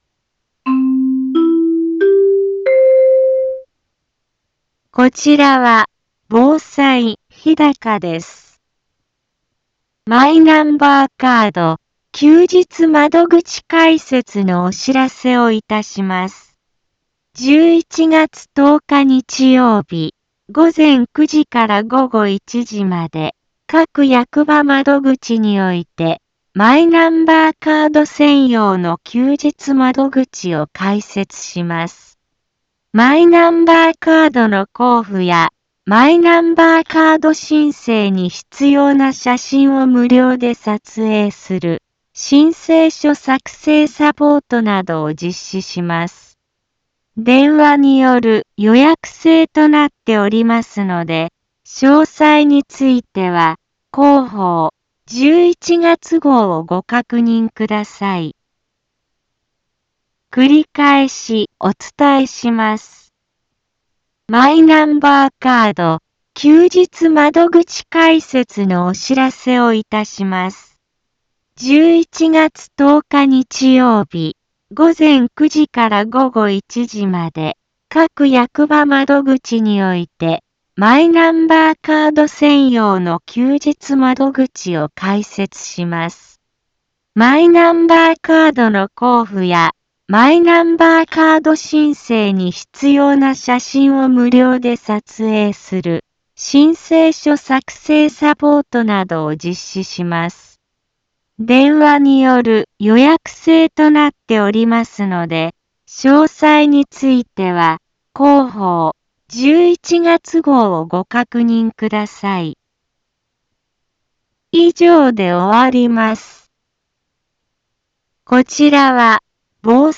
Back Home 一般放送情報 音声放送 再生 一般放送情報 登録日時：2024-11-01 10:04:28 タイトル：マイナンバーカード休日窓口開設のお知らせ インフォメーション： マイナンバーカード休日窓口開設のお知らせをいたします。 11月10日日曜日、午前9時から午後1時まで、各役場窓口において、マイナンバーカード専用の休日窓口を開設します。